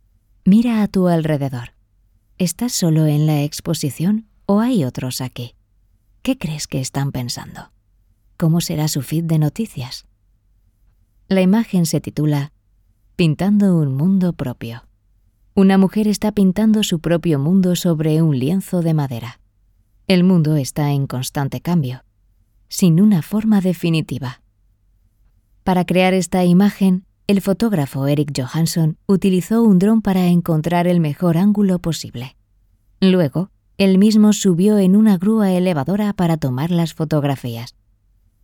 Kommerziell, Zugänglich, Vielseitig, Warm, Sanft
Audioguide